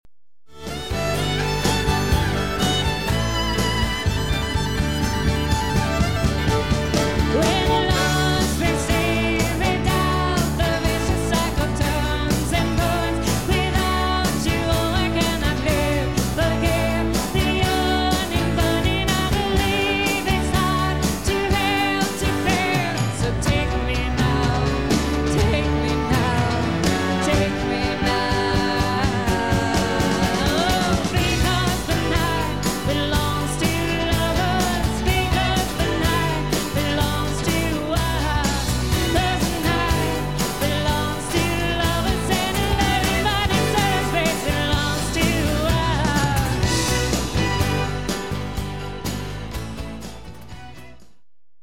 Recorded April 19, 1993, with a live audience.